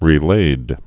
(rē-lād)